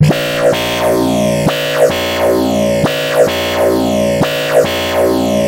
巴斯14
描述：贝斯14
标签： 175 bpm Drum And Bass Loops Bass Loops 1.24 MB wav Key : Unknown
声道立体声